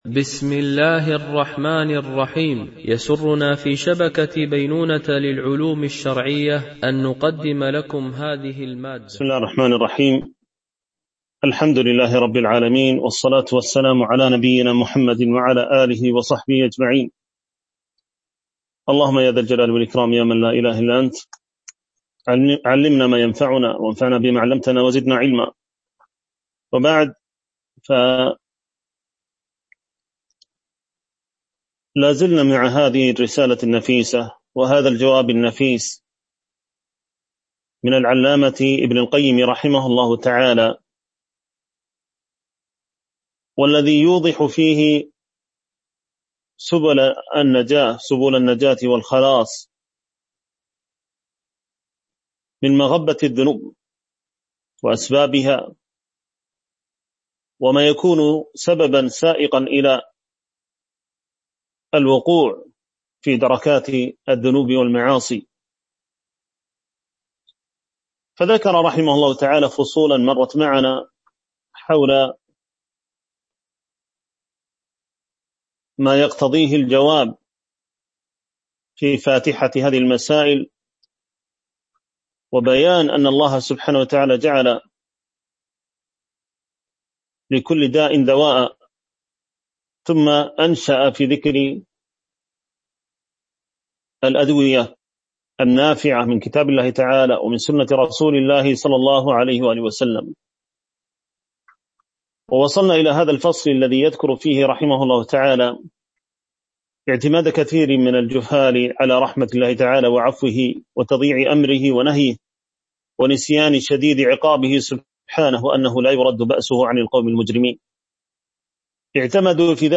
شرح كتاب الداء والدواء ـ الدرس 8
MP3 Mono 22kHz 32Kbps (CBR)